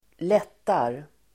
Uttal: [²l'et:ar]